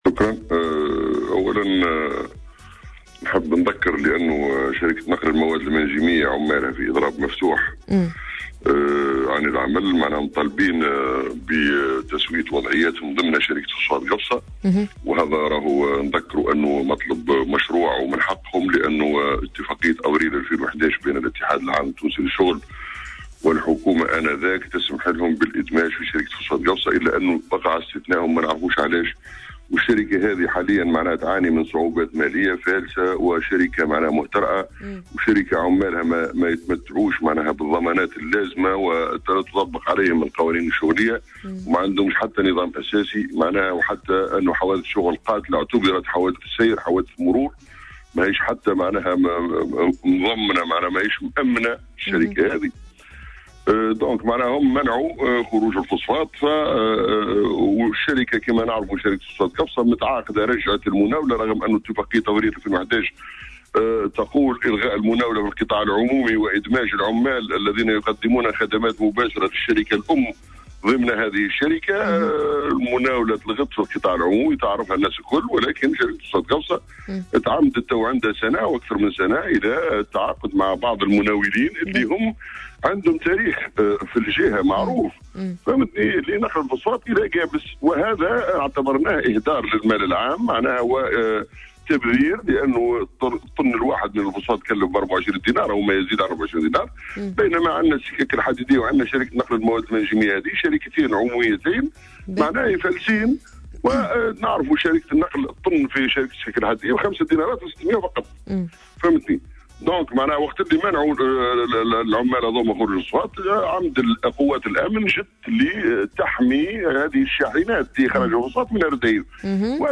علّق نائب مجلس نواب الشعب عن جهة قفصة عدنان الحاجي في تصريح اليوم ل"جوهرة أف أم" عن الأحداث الأخيرة التي تشهدها مدينة الرديف.